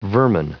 Prononciation du mot vermin en anglais (fichier audio)
vermin.wav